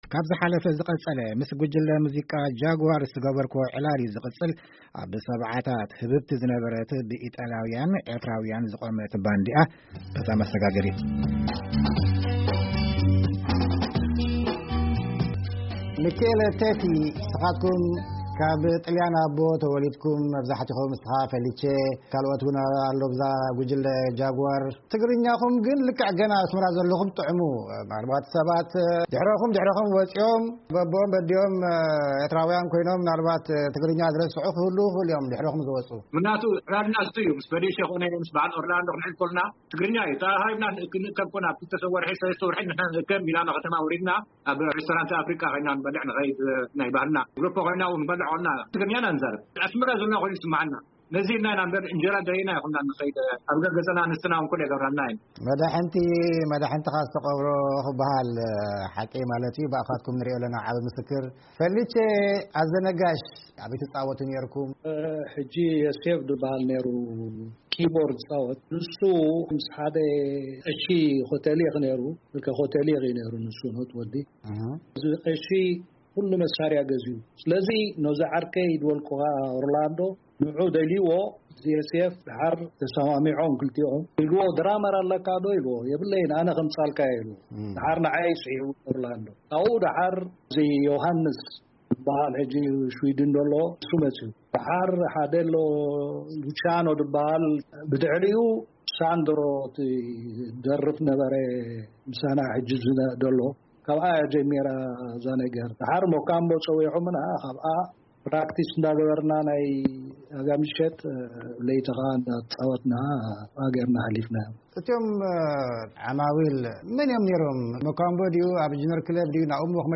ዕላል ምስ ስነ-ጥበበኛታት ዕውቲ ሰለስተ ኣህጉራዊ ውድድራት ዝኾነት ፊልም ‘ዜና ዕረፍቲ’